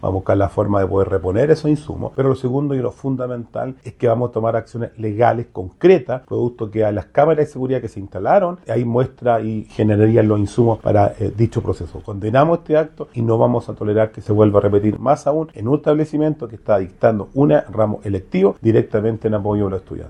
El alcalde de la comuna de Penco, Rodrigo Vera, condenó el actuar de los antisociales y señaló que iniciarán acciones legales, ya que todo quedó registrado en las cámaras de seguridad.